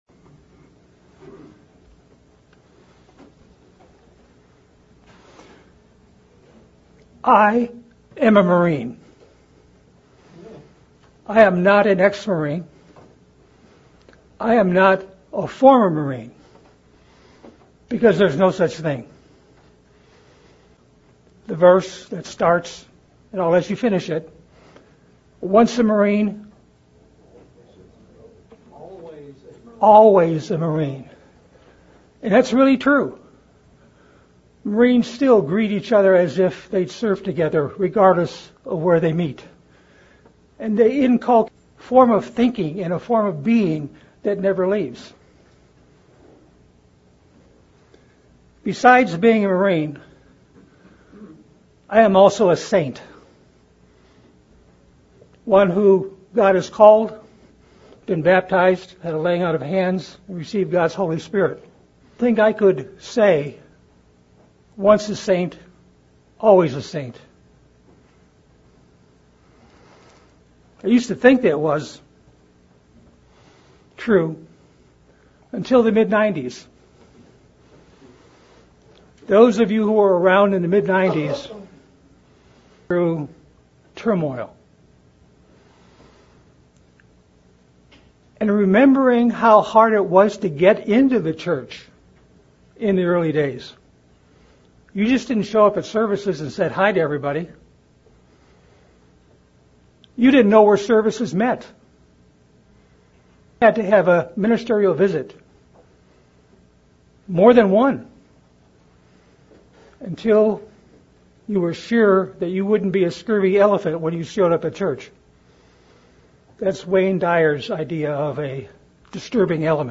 Sermons
Given in Central Illinois